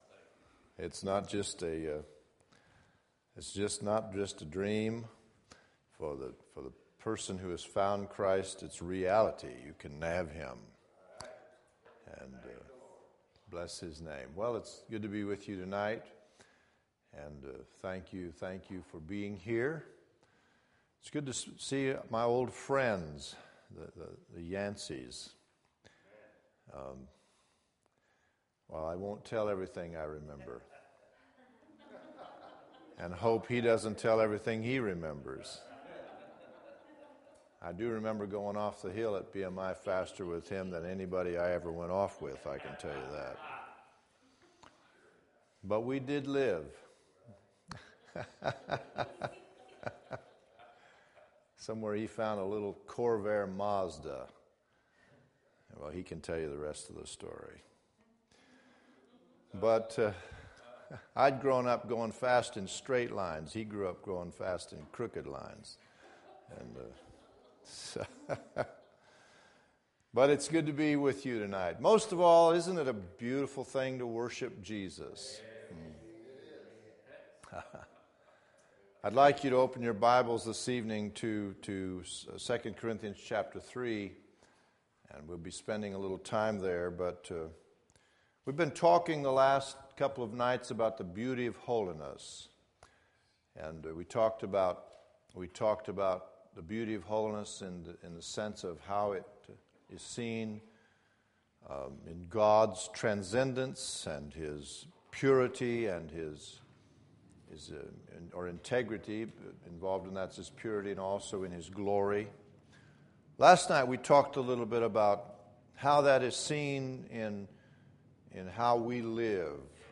Holiness Spring Revival 2011